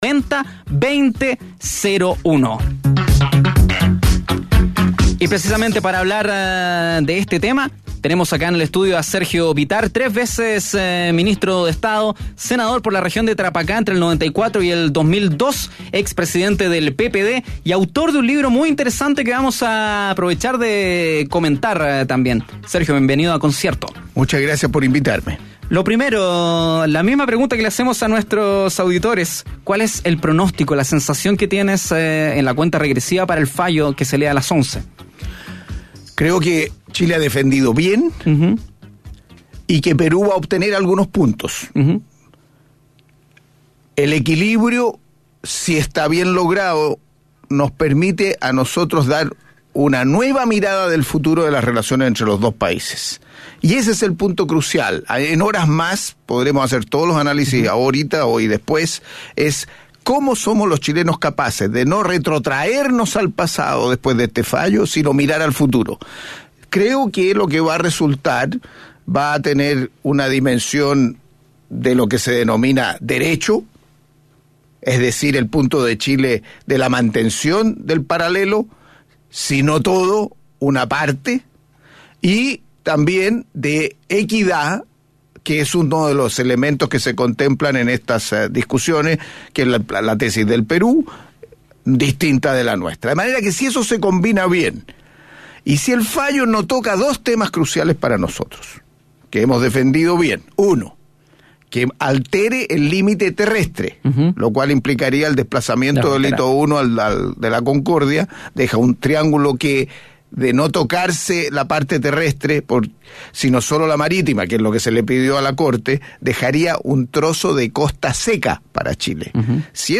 En el capítulo de día lunes de Mañana Será Otro Día, conversamos con Sergio Bitar, ex ministro y senador por Tarapacá.